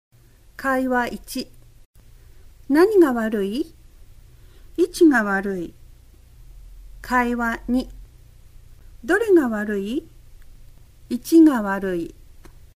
Let us take as an example the contrast between two different words that would otherwise sound the same; 位置 (“position” /i’chi/) and 一 (“one’ /ichi’/).